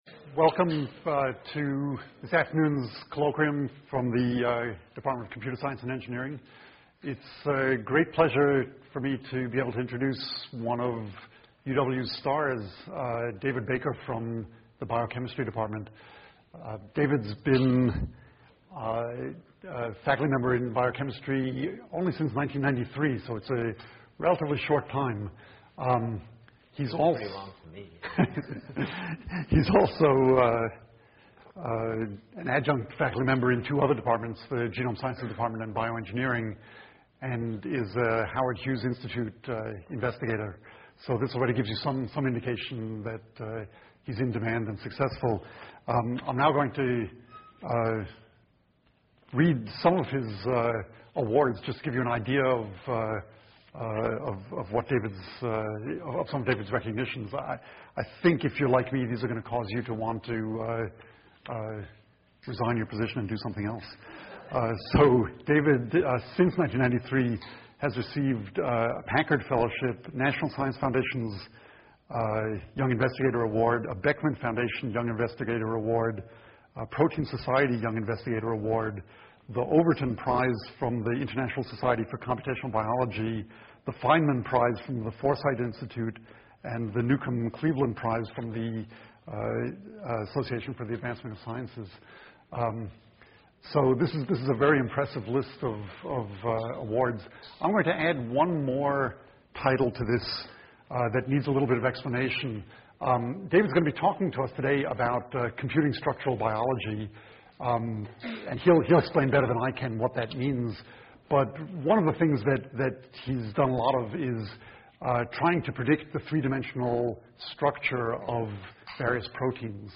Colloquium Tuesday, January 10, 2006, 3:30 pm EE-105 Abstract I will discuss our progress in predicting the structures of biological macromolecules and interactions and in designing novel macromolecules with new structures and functions, with emphasis on the key computational challenges to fully transforming structural biology from an experimental to a computational science.